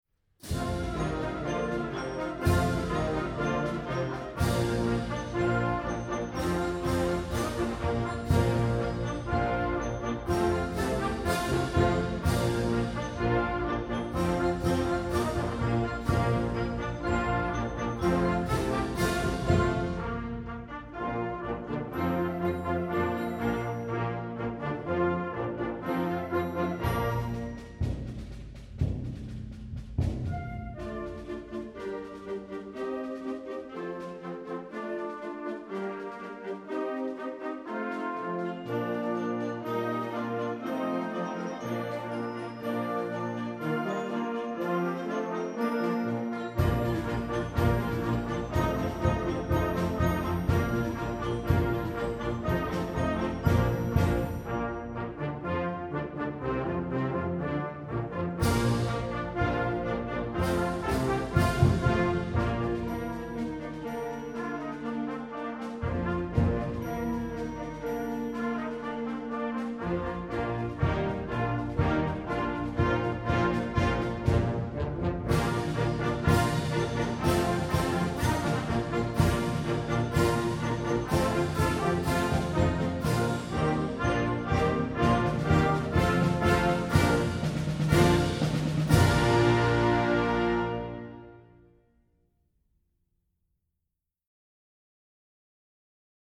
Genre: Band
Flute
Clarinet in Bb
Alto Saxophone
Tenor Saxophone
Trumpet in Bb
Horn in F
Trombone
Tuba
Timpani
Mallets
Snare Drum, Tenor Drum, Bass Drum
Crash Cymbals, Triangle